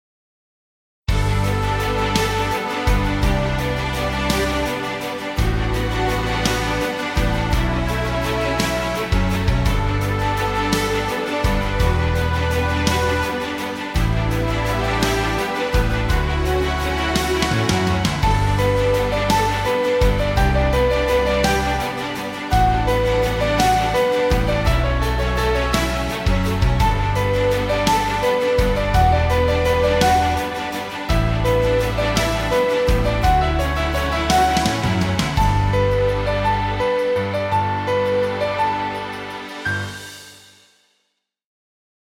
Royalty Free Music.